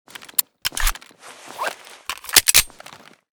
beretta_reload_empty.ogg.bak